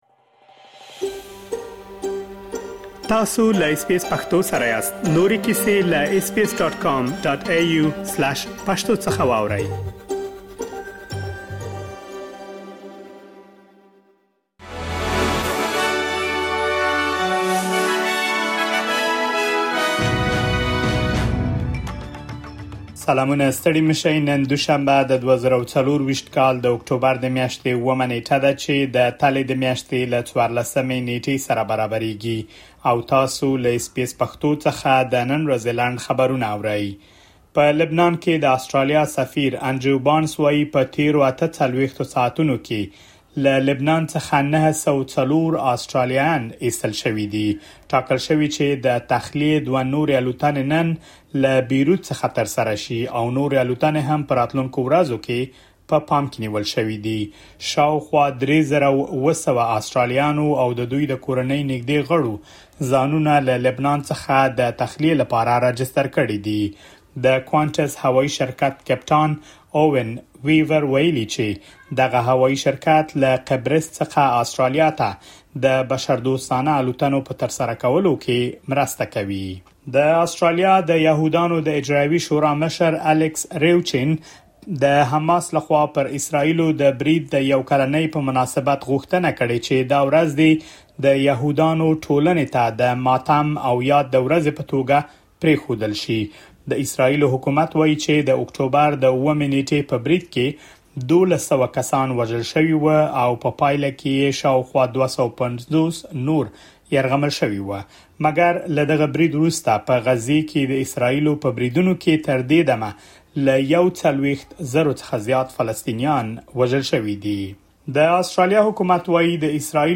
د اس بي اس پښتو د نن ورځې لنډ خبرونه|۷ اکټوبر ۲۰۲۴